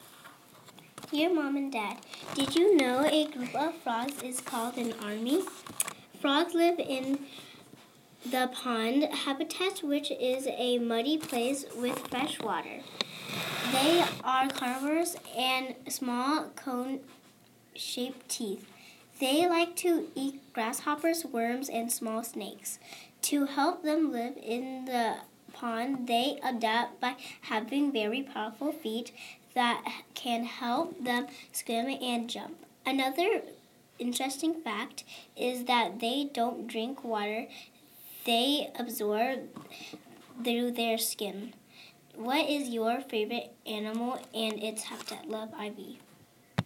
Frogs